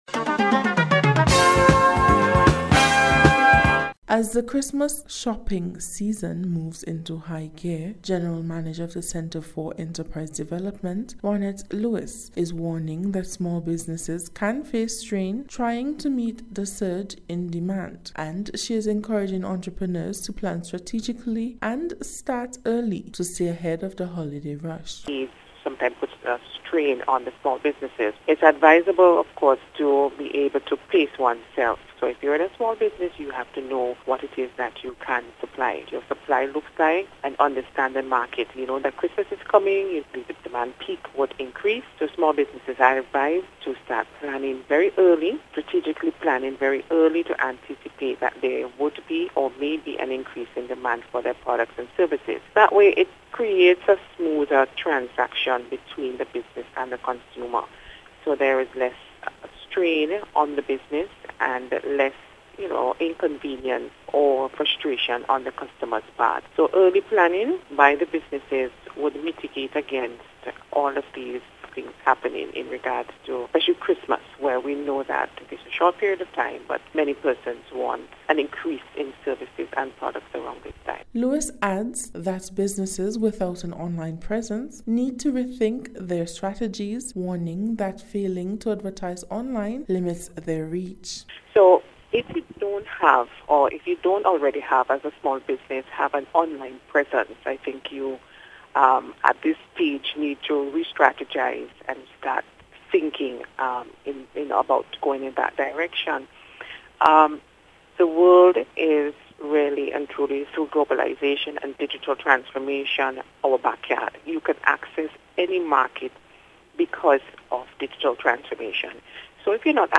CED-CHRISTMAS-REPORT.mp3